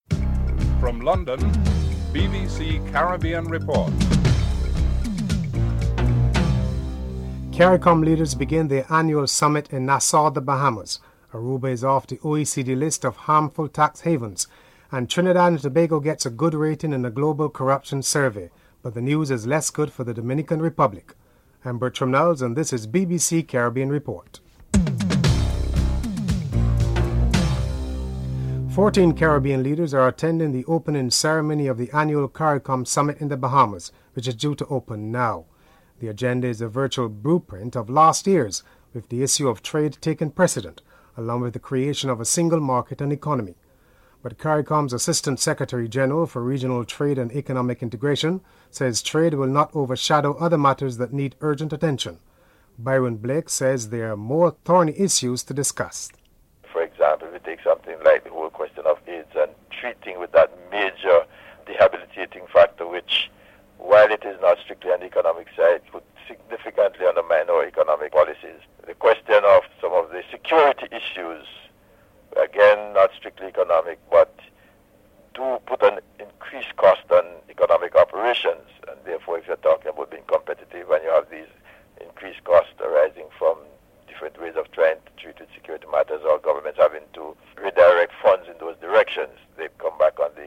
1. Headlines (00:00-00:28)
3. Venezuela's claim to the Island of Aves in the Caribbean could become an emergency item on the Caricom's Summit agenda. President Bharrat Jagdeo and Prime Minister Lester Bird are interviewed.